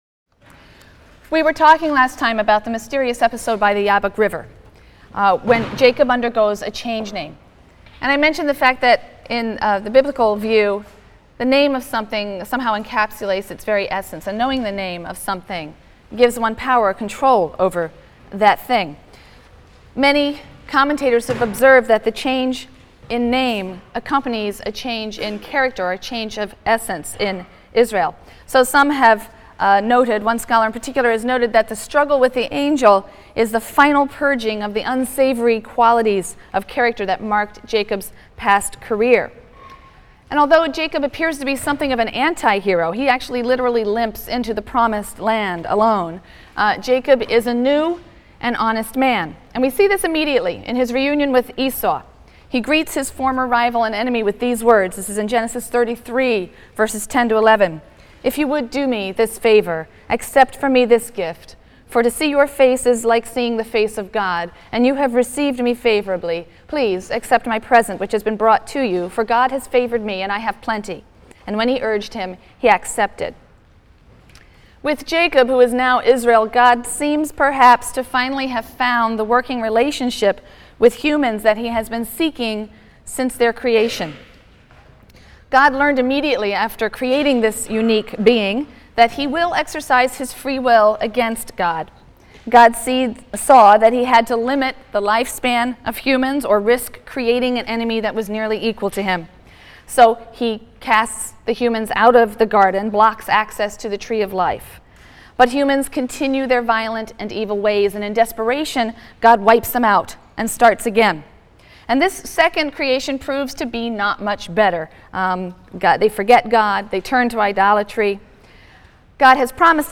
RLST 145 - Lecture 7 - Israel in Egypt: Moses and the Beginning of Yahwism (Genesis 37- Exodus 4) | Open Yale Courses